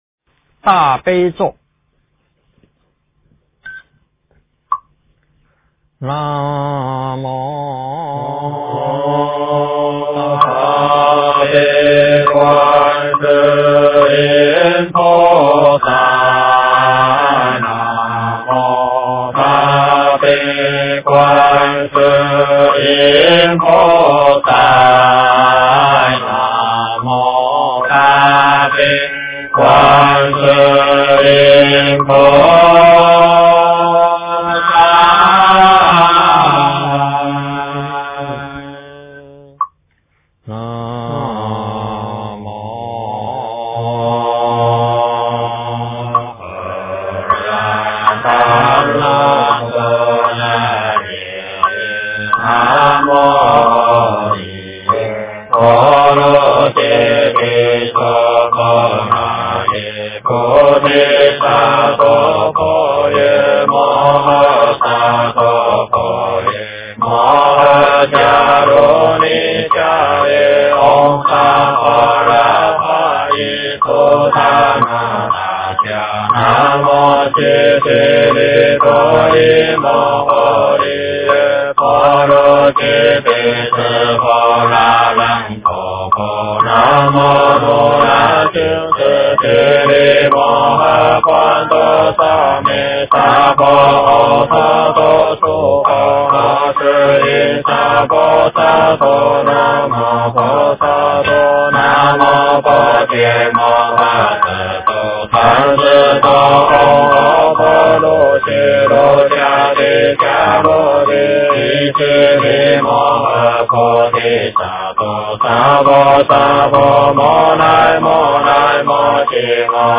经忏
佛音 经忏 佛教音乐 返回列表 上一篇： 早课--灵泉禅寺版 下一篇： 阿弥陀佛-超长版--精进佛七 1小时 相关文章 一切恭敬--佛光山梵呗研究小组 一切恭敬--佛光山梵呗研究小组...